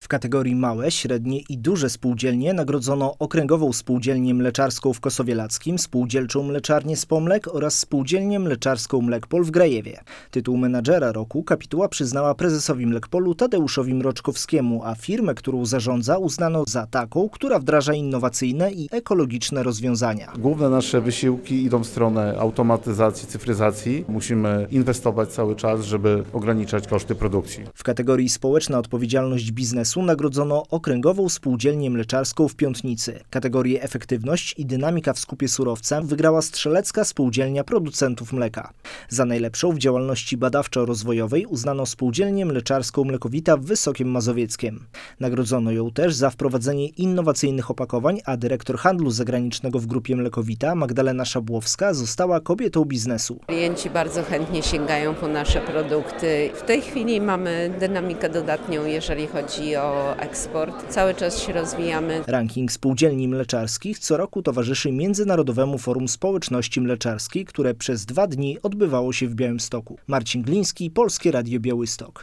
Podczas uroczystej gali w Białymstoku poznaliśmy laureatów 18. Rankingu Spółdzielni Mleczarskich.